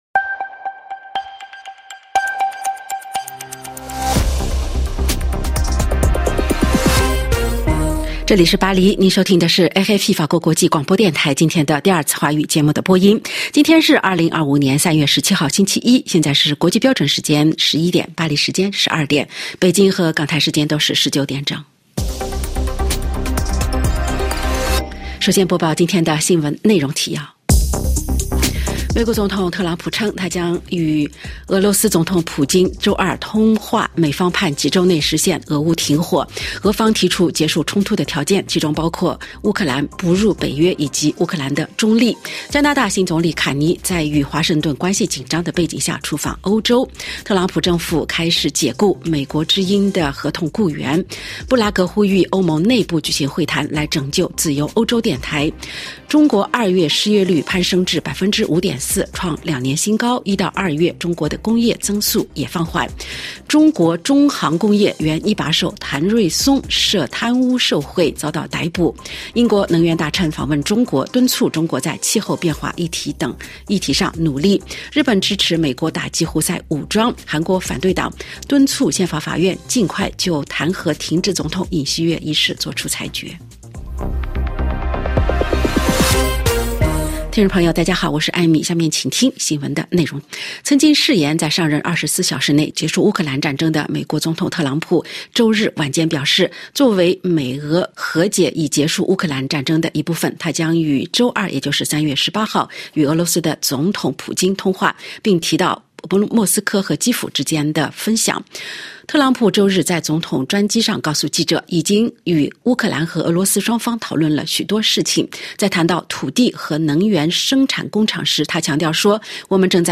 … continue reading 243集单集 # France Médias Monde # RFI - 法国国际广播电台 # 国际新闻